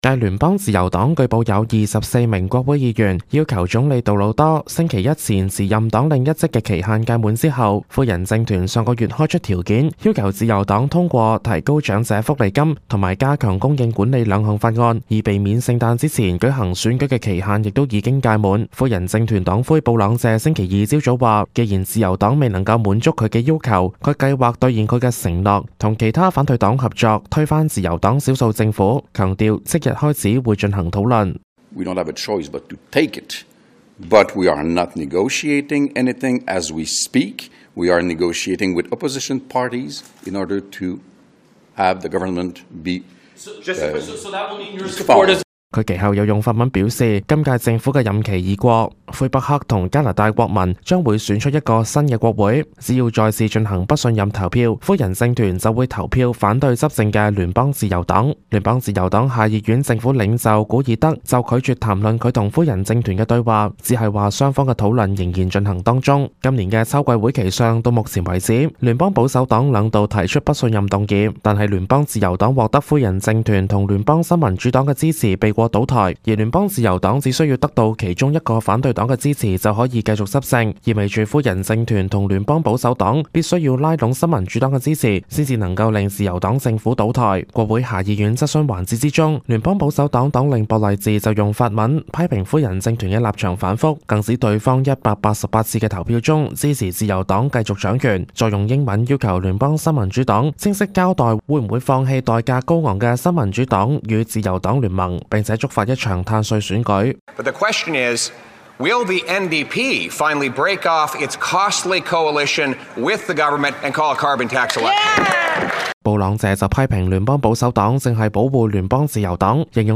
Canada/World News 全國/世界新聞